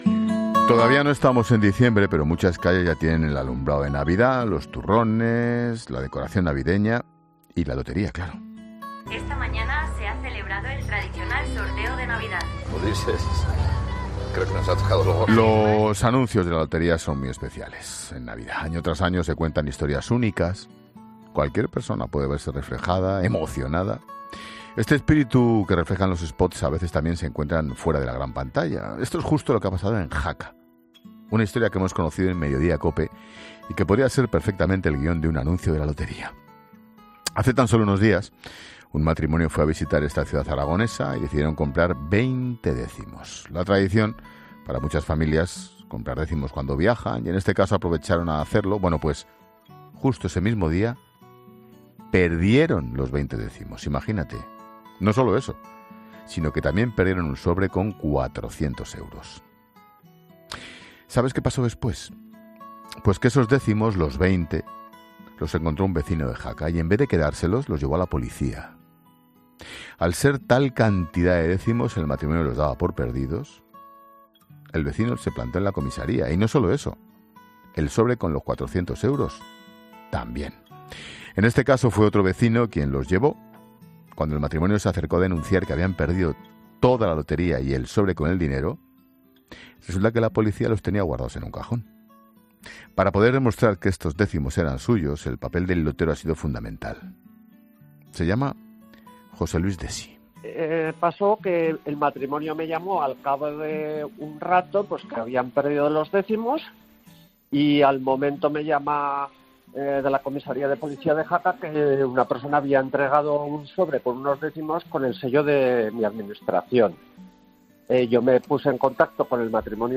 Ángel Expósito relata en La Linterna lo que ocurrió a un matrimonio en la ciudad oscense a un mes de Navidad